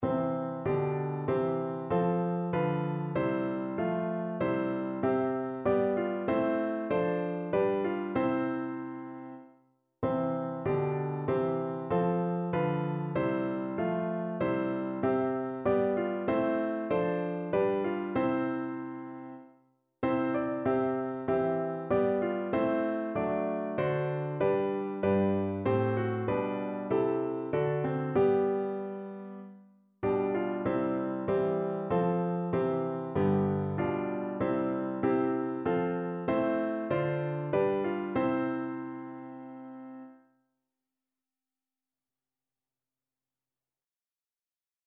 Notensatz 1 (4 Stimmen gemischt)
• gemischter Chor [MP3] 701 KB Download